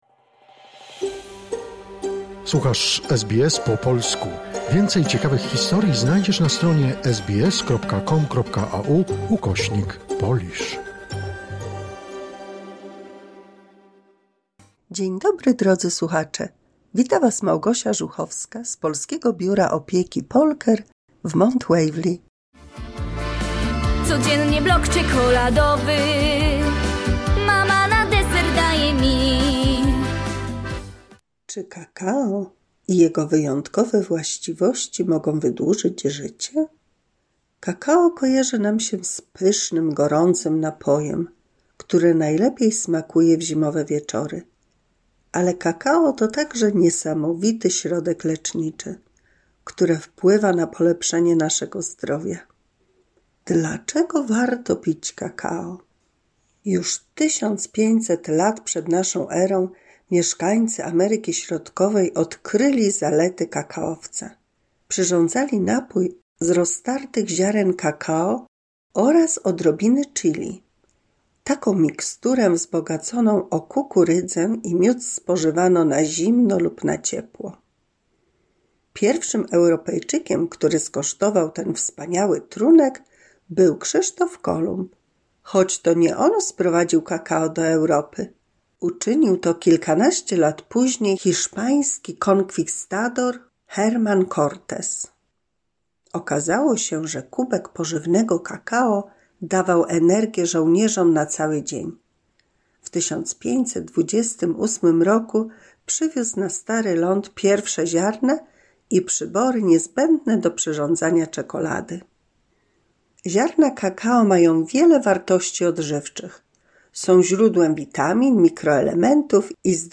182 słuchowisko dla polskich seniorów